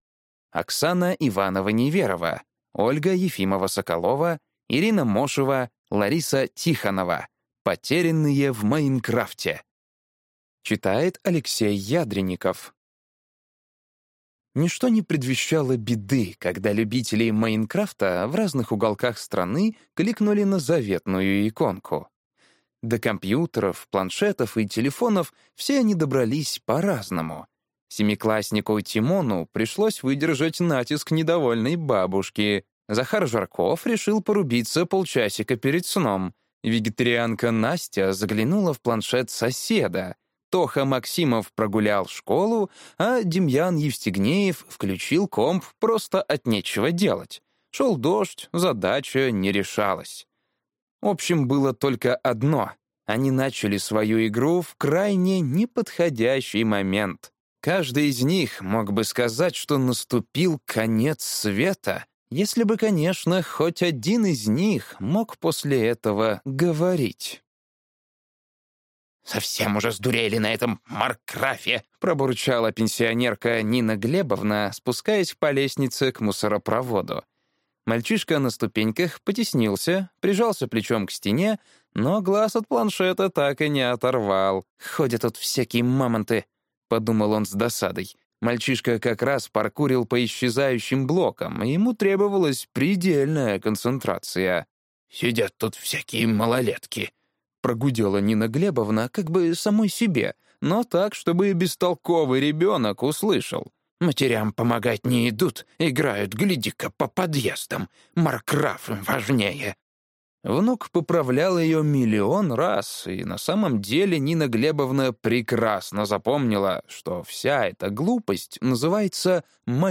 Аудиокнига Потерянные в Майнкрафте | Библиотека аудиокниг